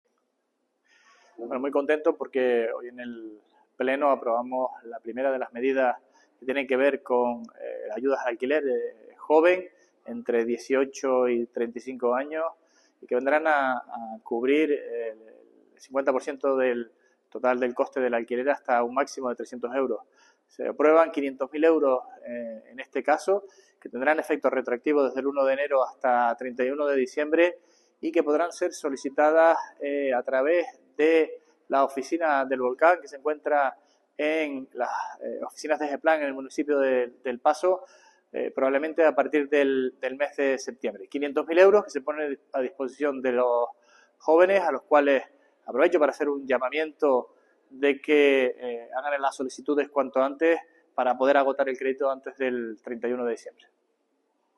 Declaraciones Sergio Rodríguez alquiler joven audio.mp3